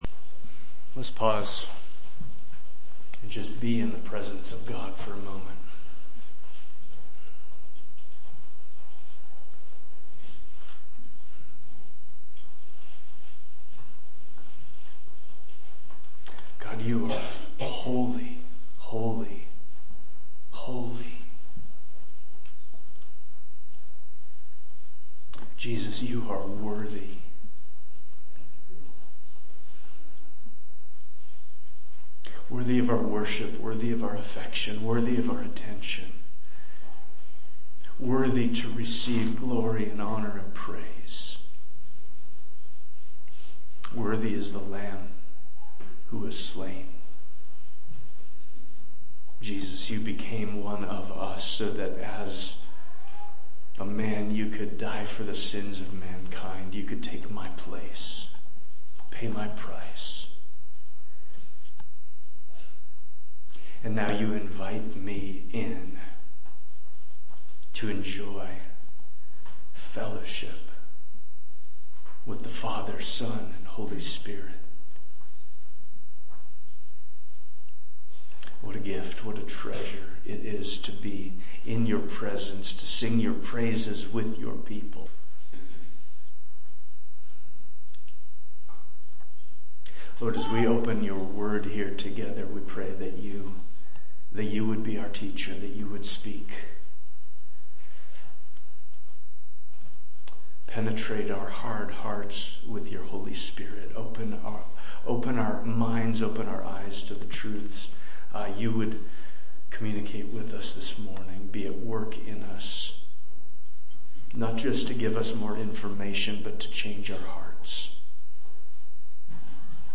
Preaching from the Pulpit of Ephraim Church of the Bible